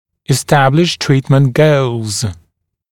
[ɪs’tæblɪʃ ‘triːtmənt gəulz] [es-][ис’тэблиш ‘три:тмэнт гоулз] [эс-]установить, определить цели лечения